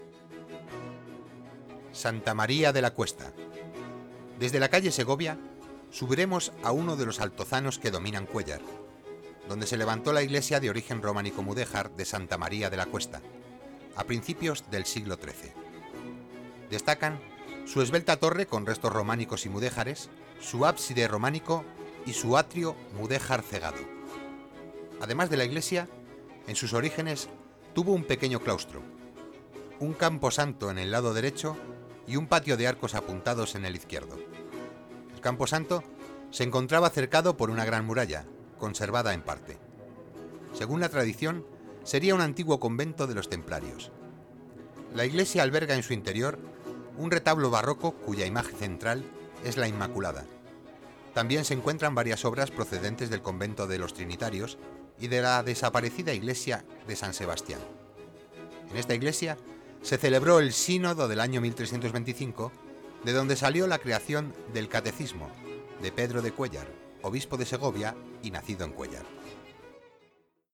audio guías